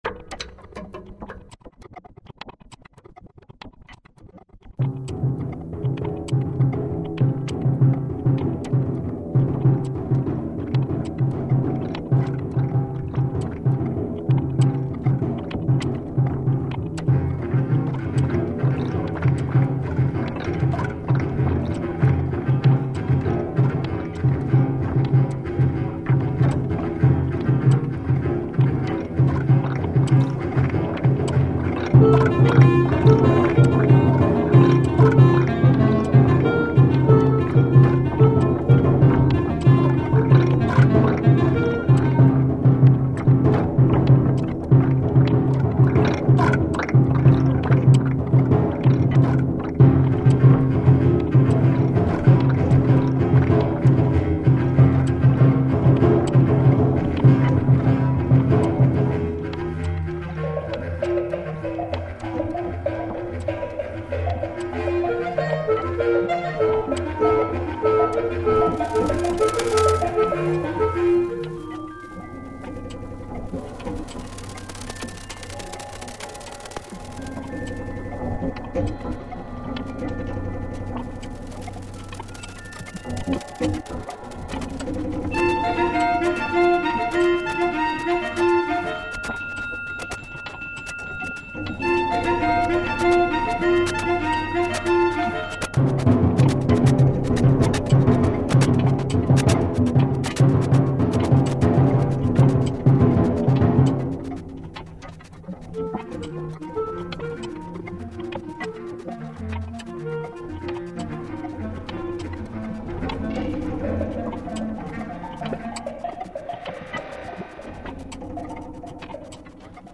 for Bb clarinet, bass clarinet, percussion and electronic